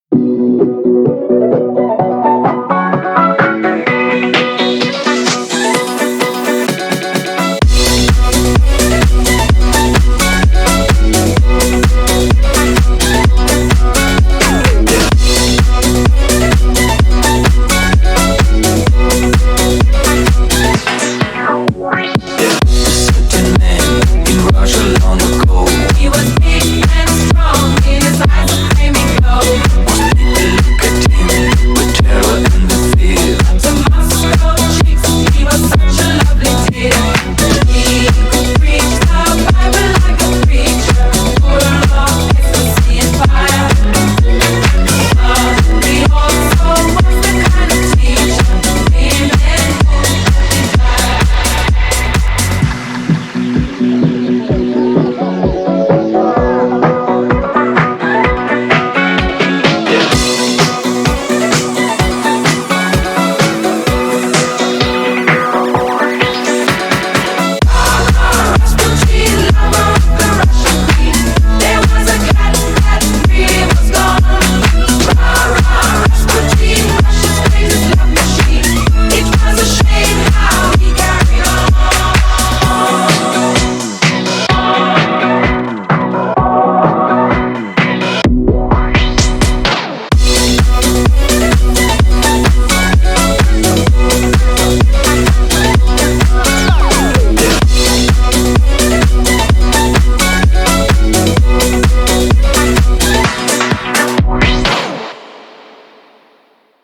BPM128
Audio QualityCut From Video